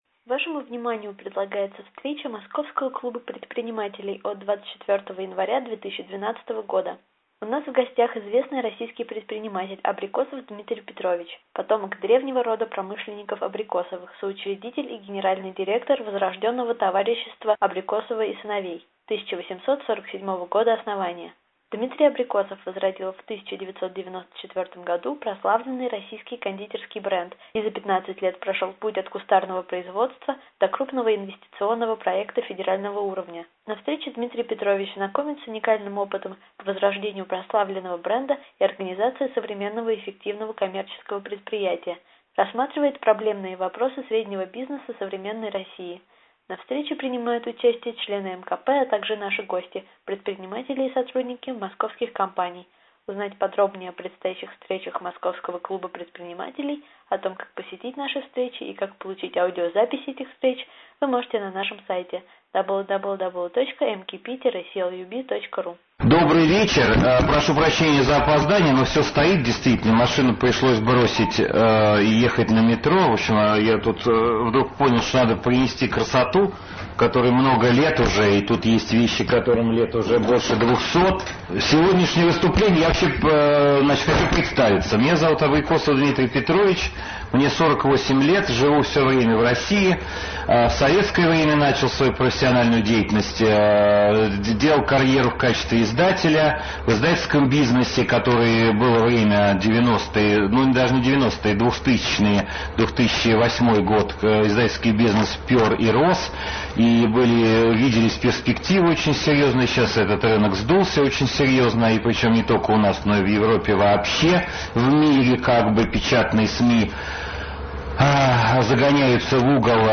24 января 2012 года прошла первая встреча Московского клуба предпринимателей в 2012 году.